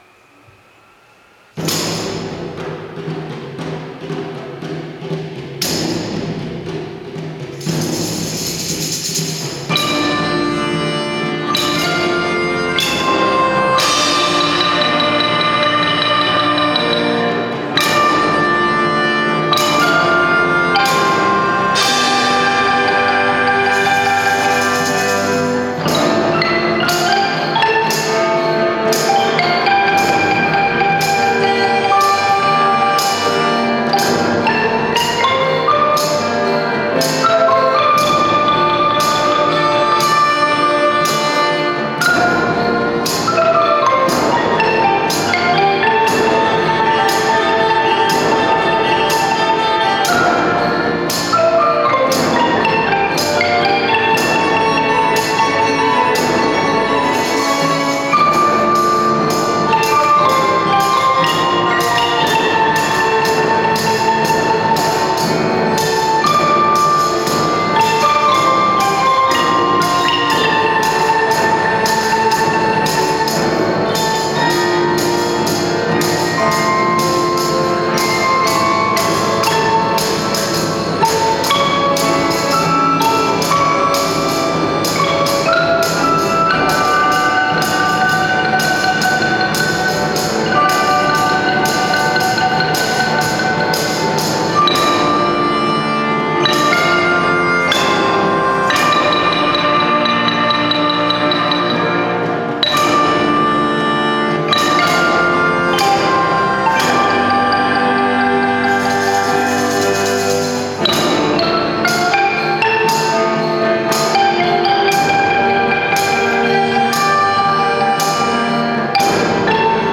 ６年生からはお返しとして、家庭科で作成した雑巾を贈り、合奏でアフリカンシンフォニーを演奏しました。
合奏の音を添付しておりますので、是非お聴きください！